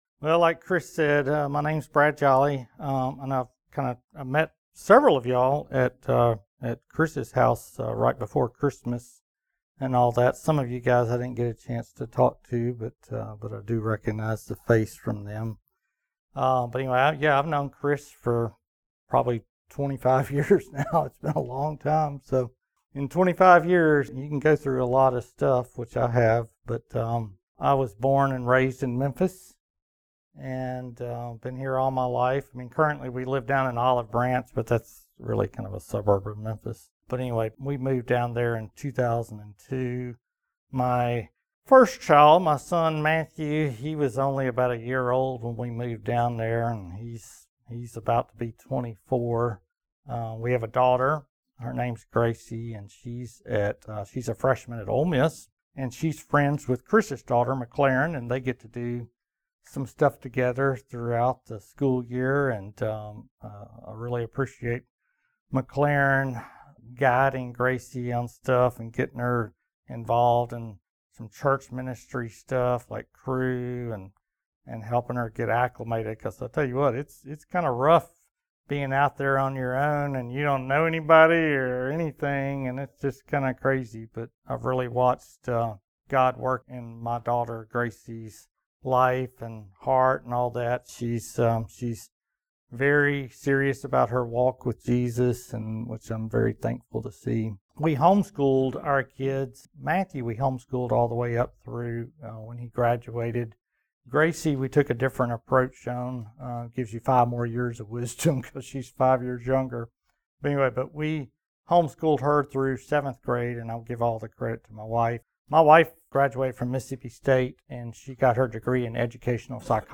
Personal Testimony